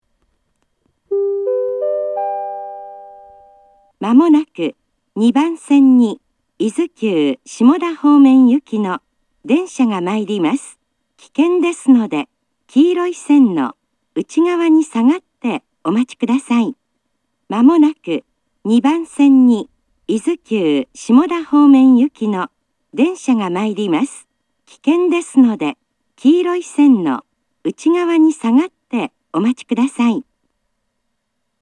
伊豆急仙石型
（女性）
主に特急が通過する際に聞けます。
稀に普通列車でも聞けます。
下り接近放送
Panasonic縦型　1・2番線